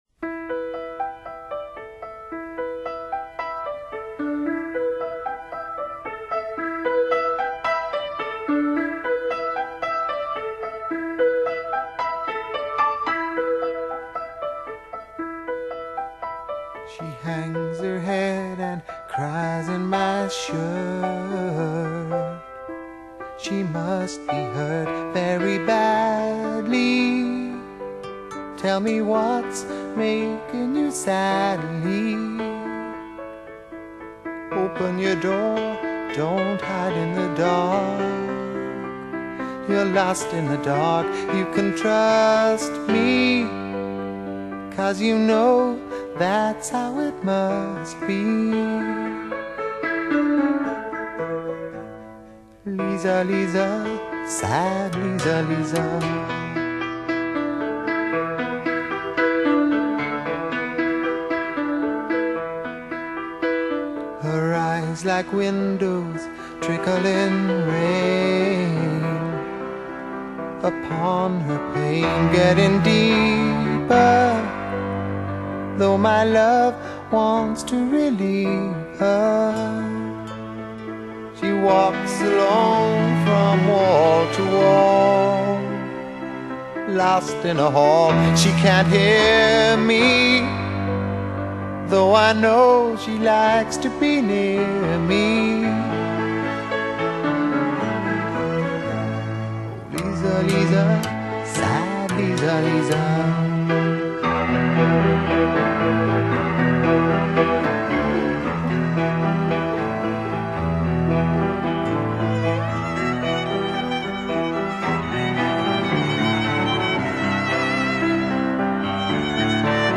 Format: Extra tracks, Original recording remastered